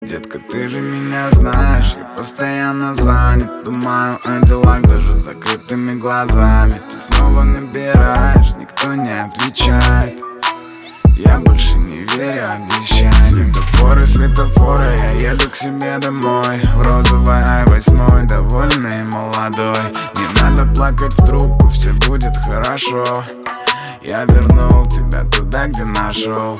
• Качество: 320, Stereo
поп
мужской вокал
Хип-хоп